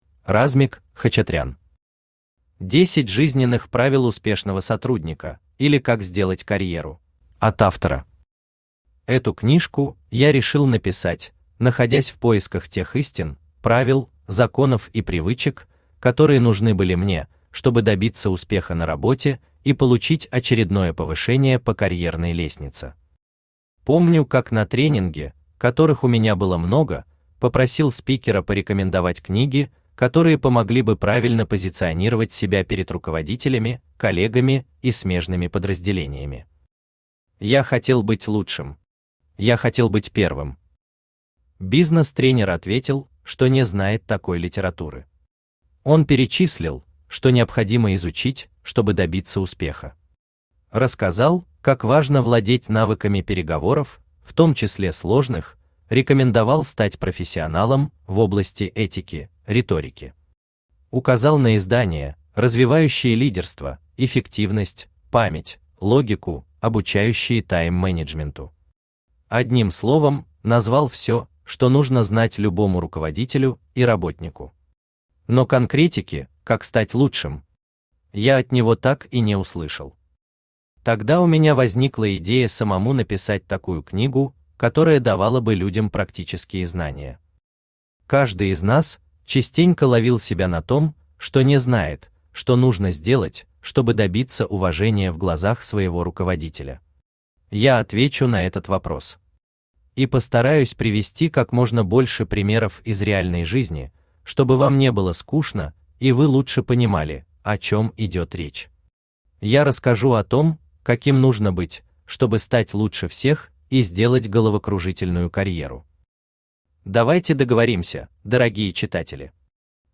Аудиокнига 10 Жизненных правил Успешного сотрудника, или как сделать Карьеру!
Автор Размик Хачатрян Читает аудиокнигу Авточтец ЛитРес.